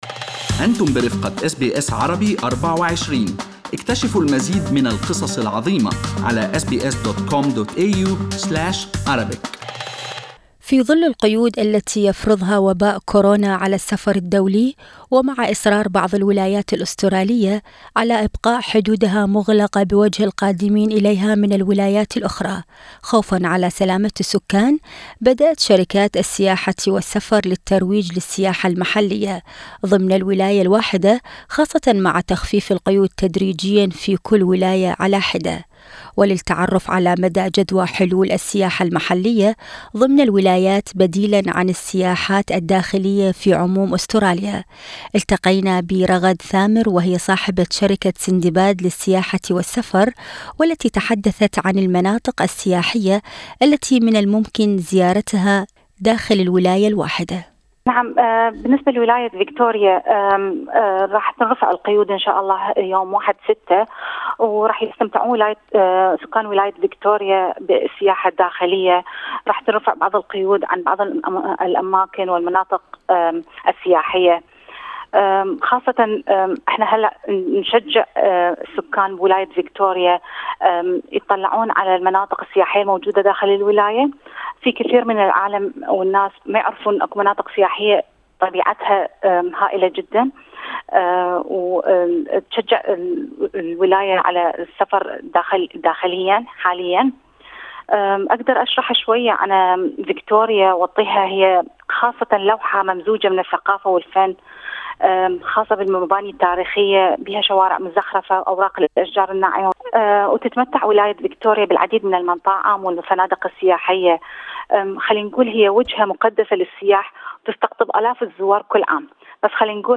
استمع إلى المزيد في التقرير الصوتي اعلاه يجب على الجميع في أستراليا البقاء على مسافة 1.5 متر على الأقل من أي شخص آخر.